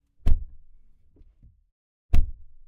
Dodge_Challenger_Shaker_SRT8_t10_Var_SFX_Door_Interior.ogg